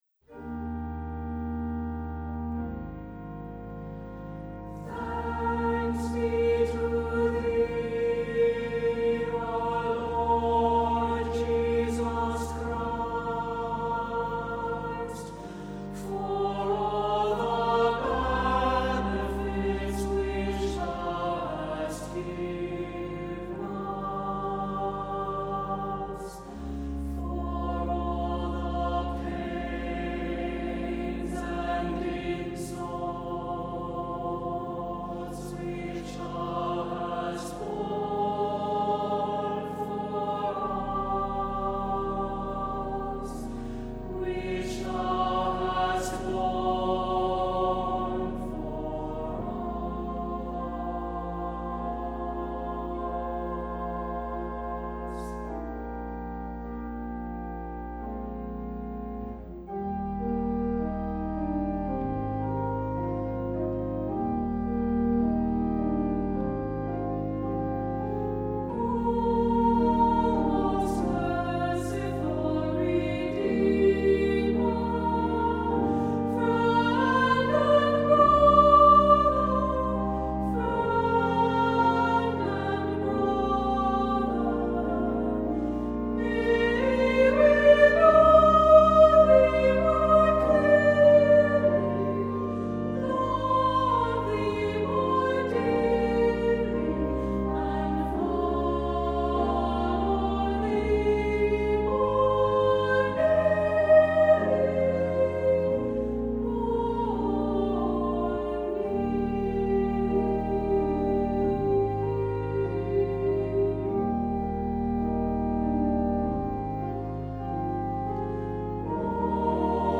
Accompaniment:      With Organ
Music Category:      Christian
SATB, organ